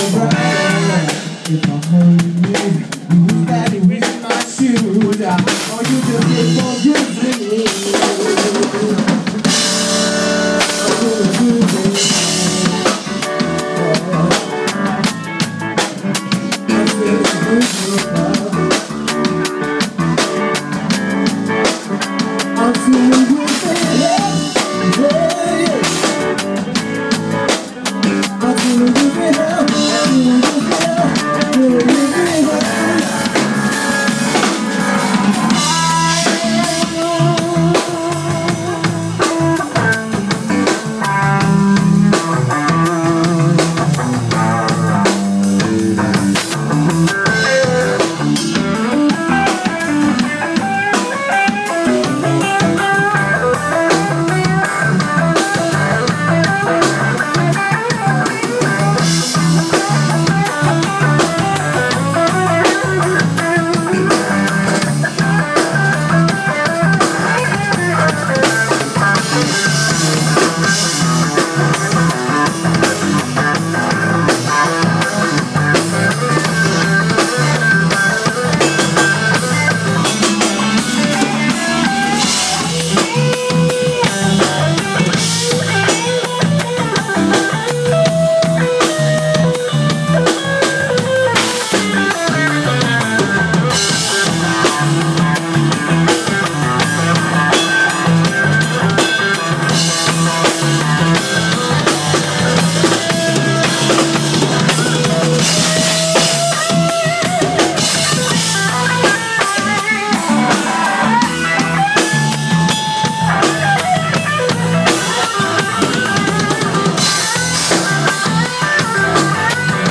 Live in Barga - 4th night - PIazza del Crocifisso